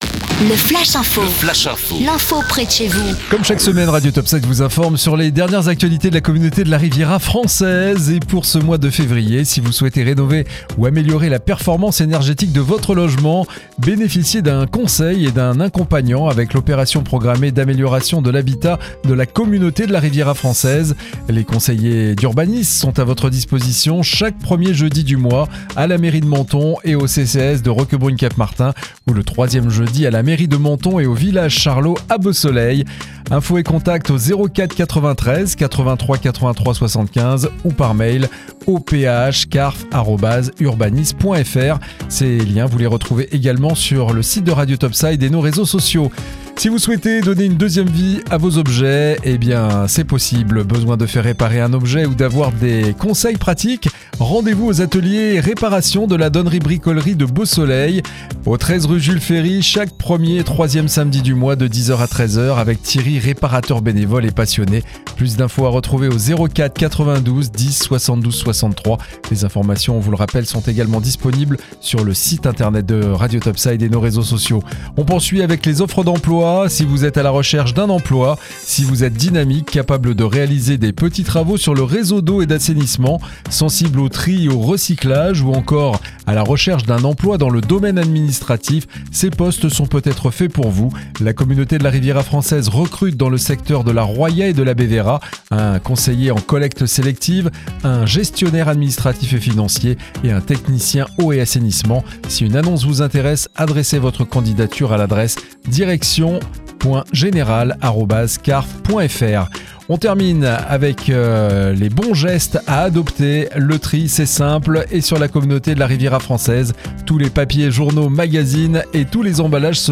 C.A.R.F ACTU - LE FLASH INFO FEVRIER 2026